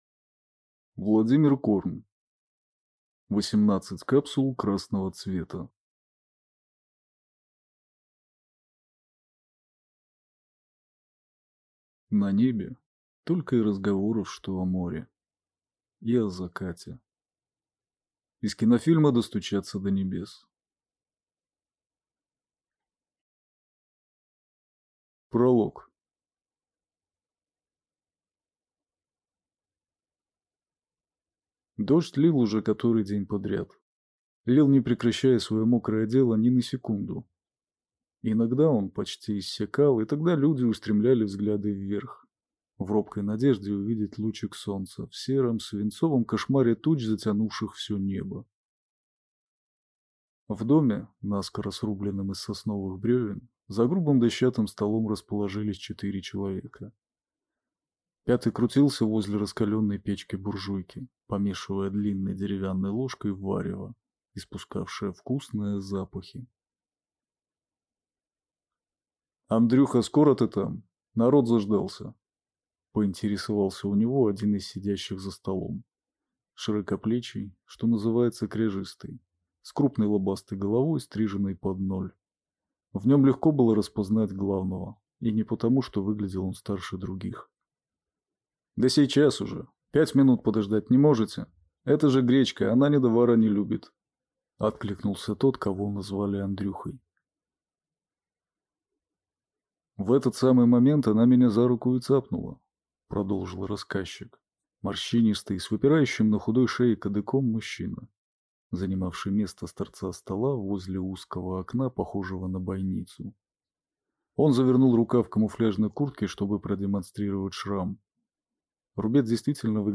Аудиокнига Восемнадцать капсул красного цвета | Библиотека аудиокниг